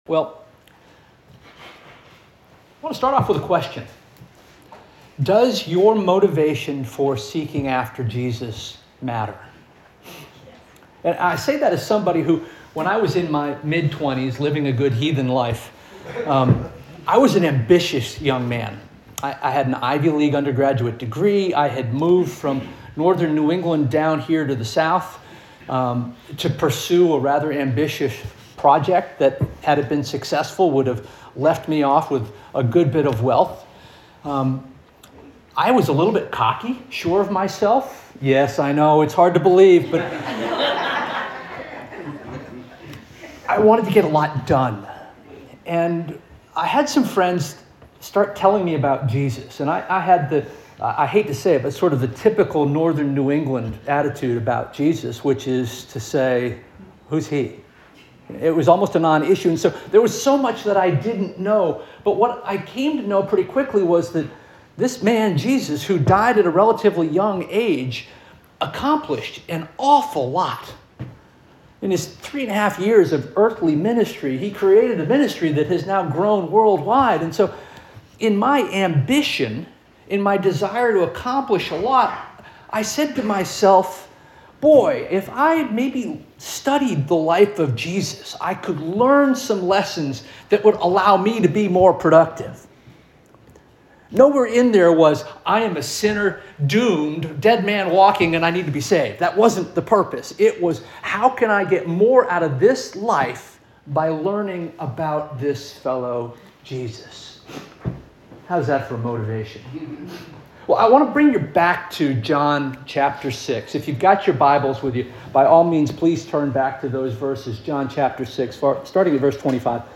January 18 2026 Sermon - First Union African Baptist Church